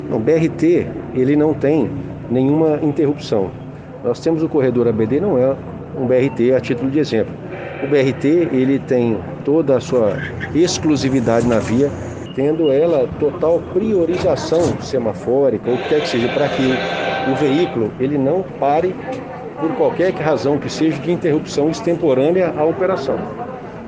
A informação é do secretário dos Transportes, Alexandre Baldy, em entrevista exclusiva ao Diário do Transporte, nesta terça-feira, 20 de abril de 2021.